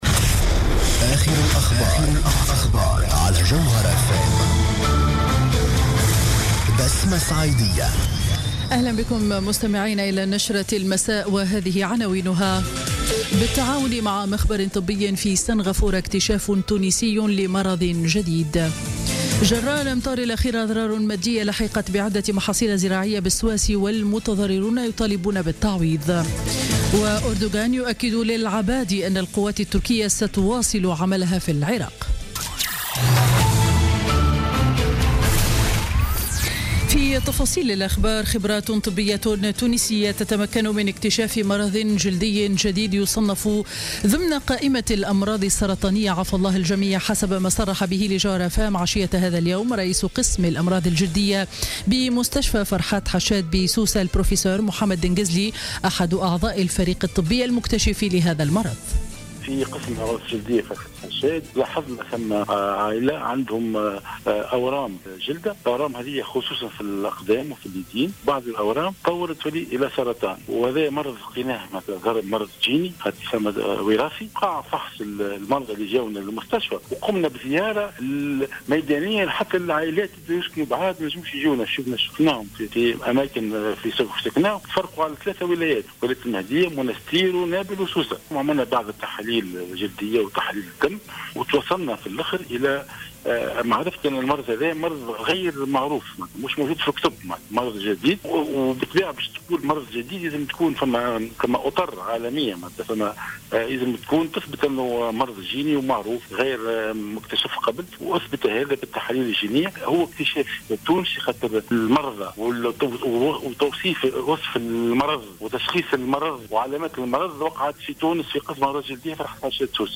نشرة أخبار السابعة مساء ليوم الثلاثاء 11 أكتوبر 2016